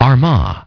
Транскрипция и произношение слова "armagh" в британском и американском вариантах.